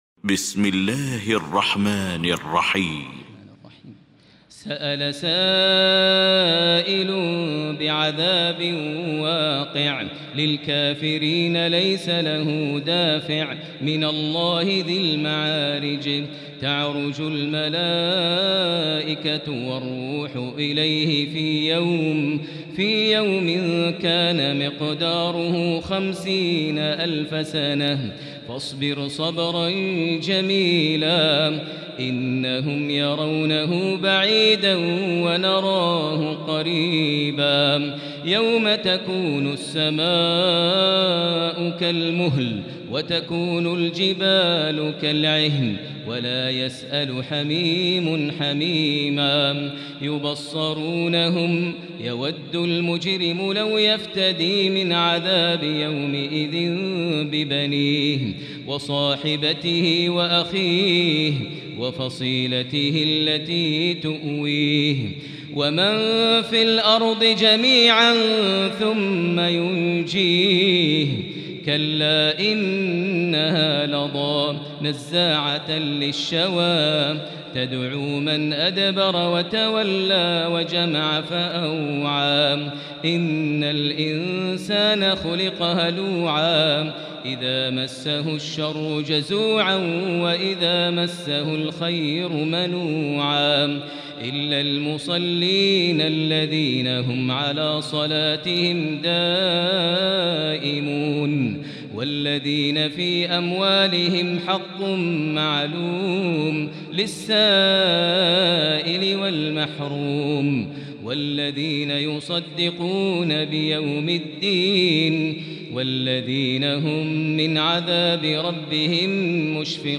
المكان: المسجد الحرام الشيخ: فضيلة الشيخ ماهر المعيقلي فضيلة الشيخ ماهر المعيقلي المعارج